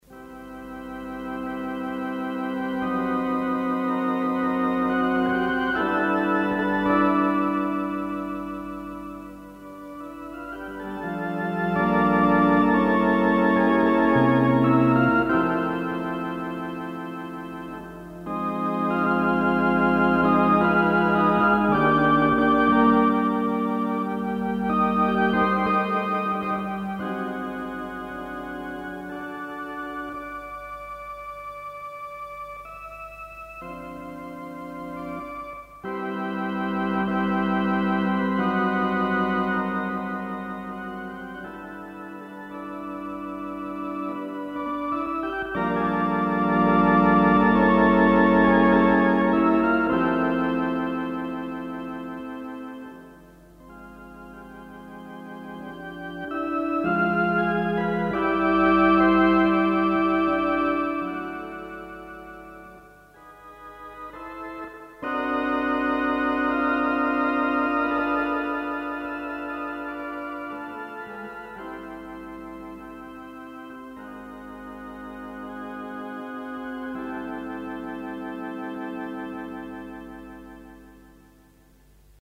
Philicorda
As the recording bug was already well installed (audio only in those days!) a few tape records were made – some of which have survived the intervening years and multiple shifts in technology and media standards.
philicorda_kh_musings1.mp3